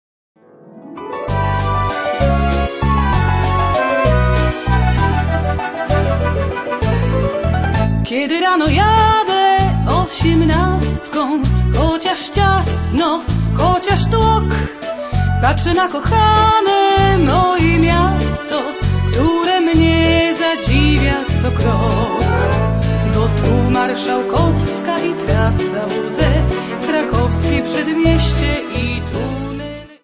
Folk Tangos and Waltzes.